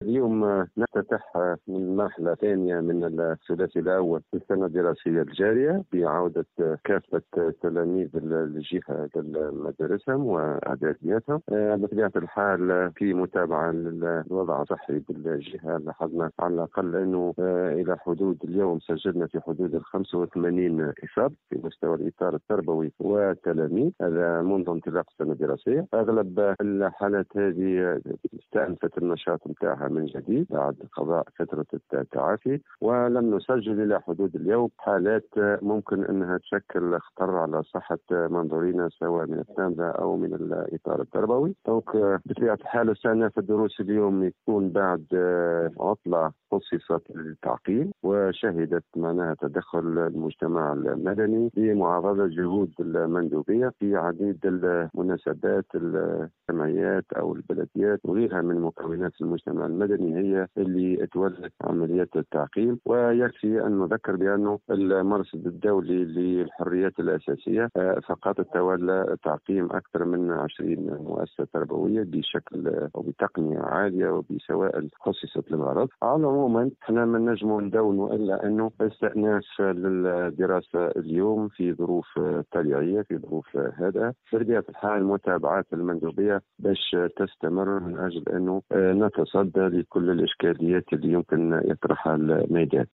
كما تم تسجيل 50 حالة شفاء وفق ما أكده منصف القاسمي المندوب الجهوي للتربية بالقصرين في تصريح لمراسل الجوهرة "أف أم".و أضاف القاسمي أنه تم خلال العطلة المنقضية تعقيم المؤسسات التربوية من طرف مصالح المندوبية بالشراكة مع مكونات المجتمع المدني و البلديات في معاضدة لمجهودات المندوبية الجهوية للتربية .